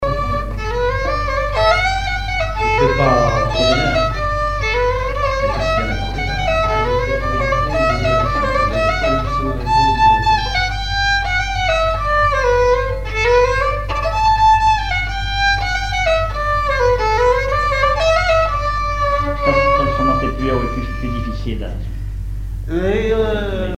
danse : branle : avant-deux
chansons populaires et instrumentaux
Pièce musicale inédite